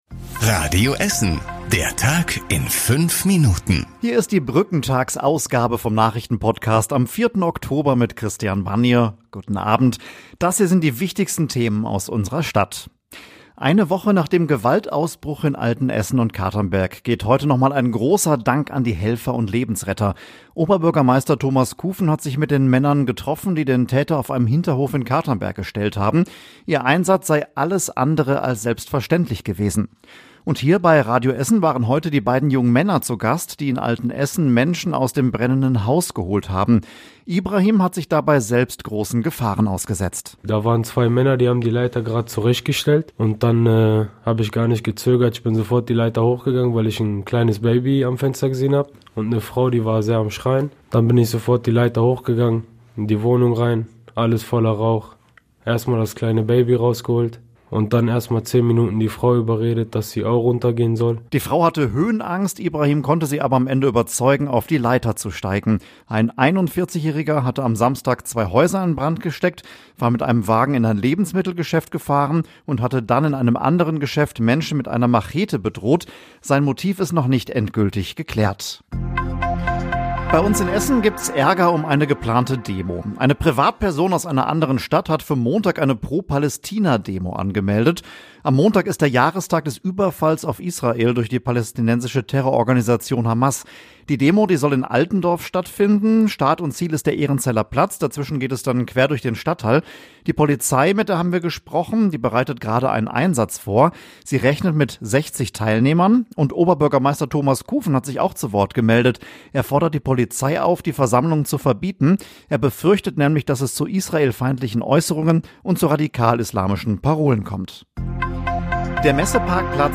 Lebensretter aus Altenessen im Interview + Ärger um Pro-Palästina-Demo + Spielemesse ist Publikumsrenner
Die wichtigsten Nachrichten des Tages in der Zusammenfassung